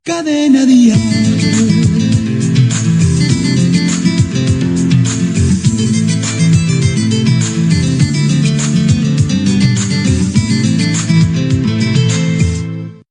Indicatiu de l'emissora.